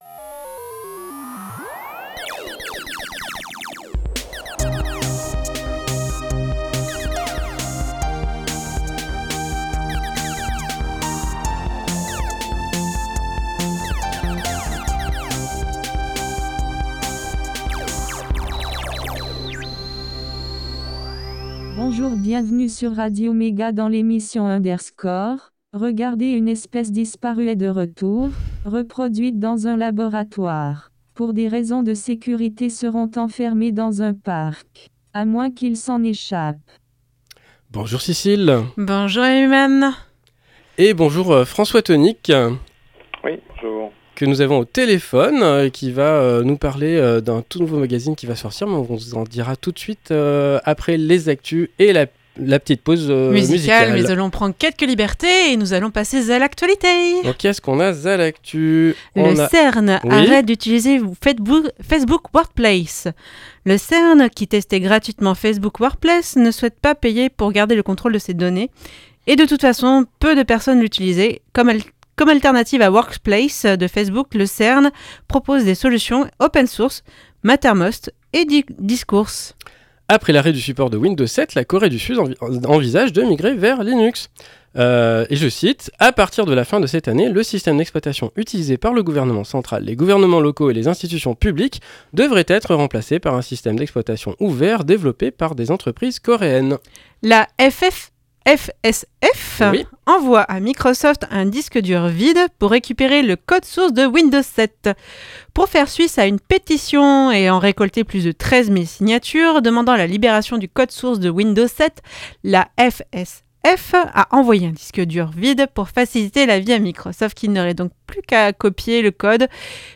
interview
une pause chiptune